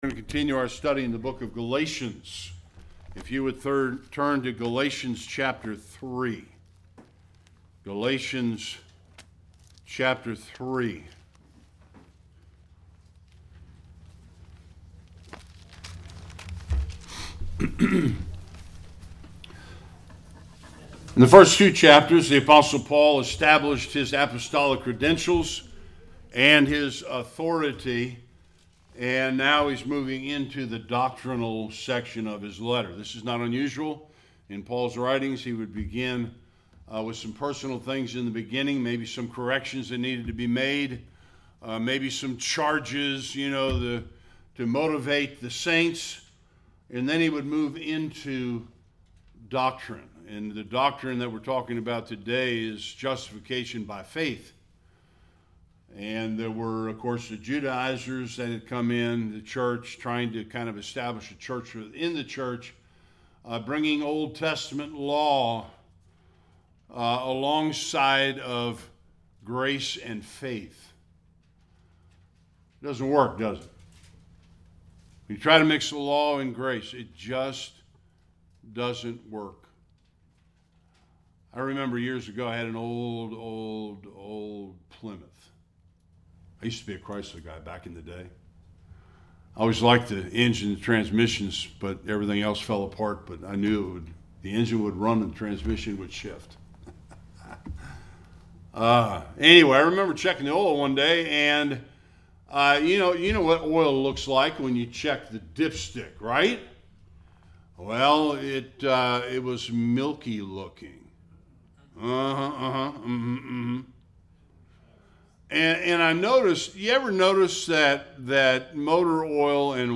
1-14 Service Type: Sunday Worship The Judaizers wanted Gentile converts to be placed under the Law of Moses.